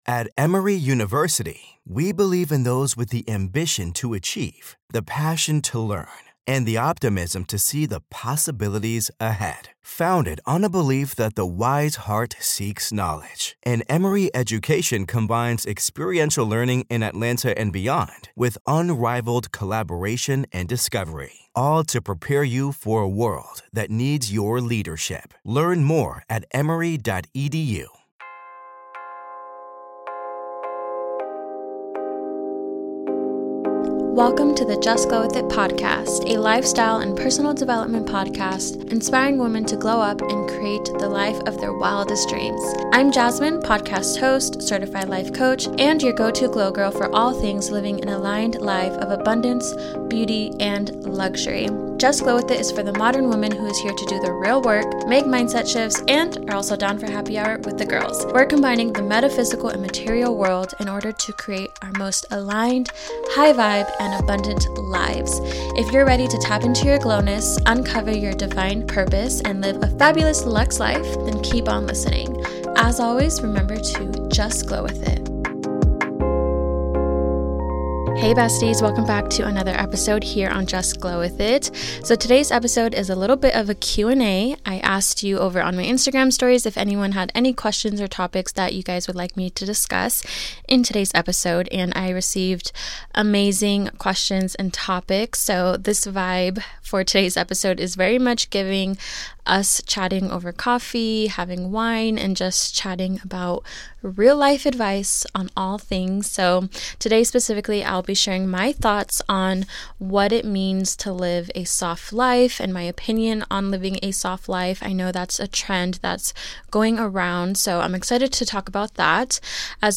Today's episode is a Q+A on my thoughts on living a soft life, embracing my feminine energy, how to overcome jealousy and feeling confident while in a relationship.